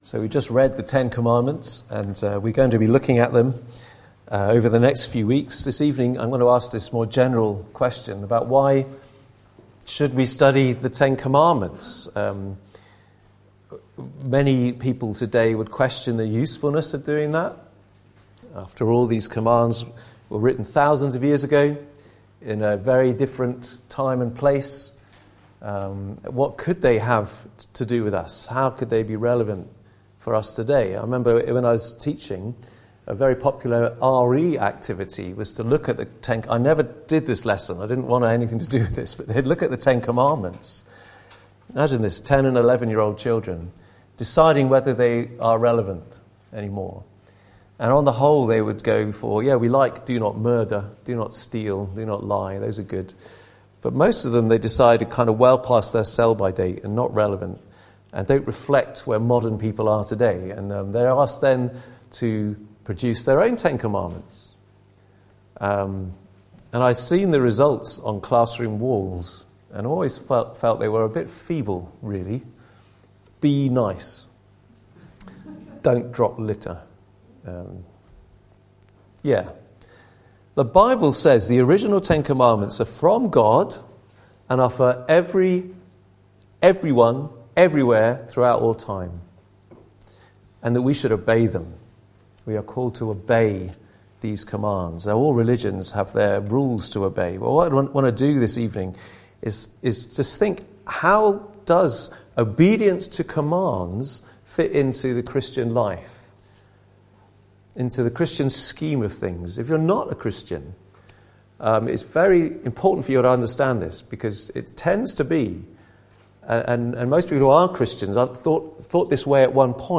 The 10 Commandments Passage: Exodus 20:1-17 Service Type: Sunday Evening « Learn to Tremble